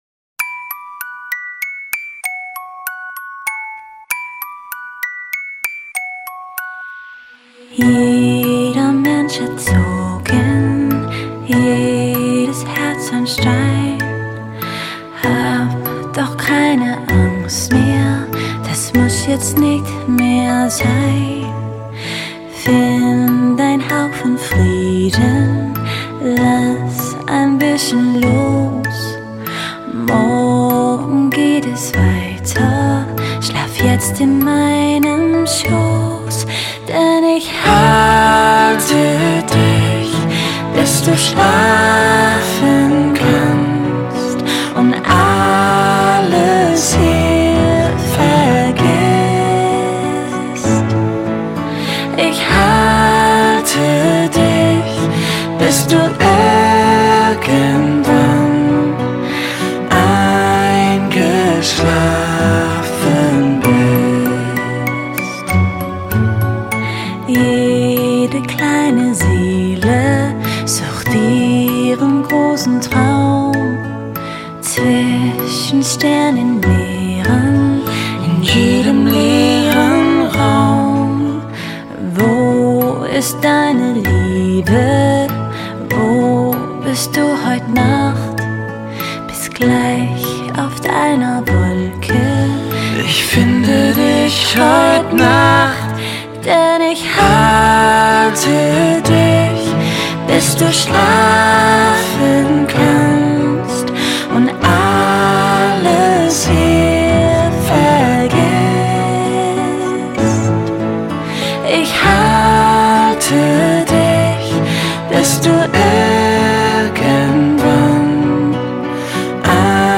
歌曲舒缓平静伤感而又动听
一高一低的旋律转换充分体现了彼此几近融合的唱腔